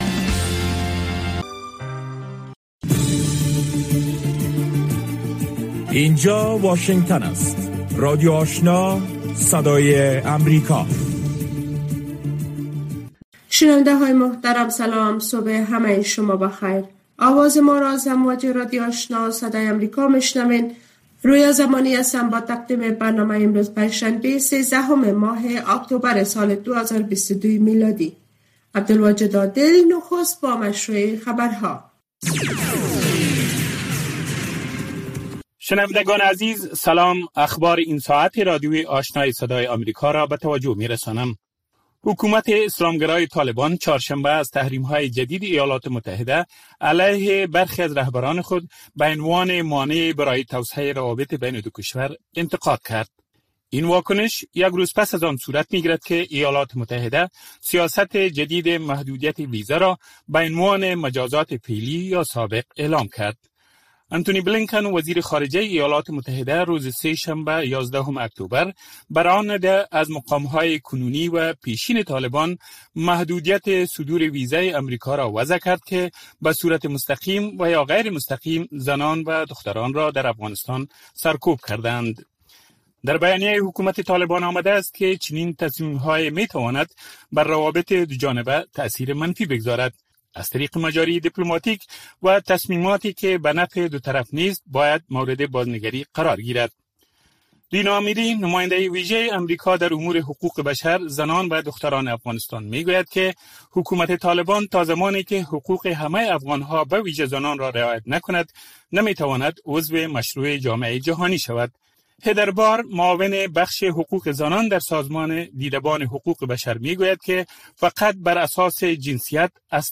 برنامۀ خبری صبحگاهی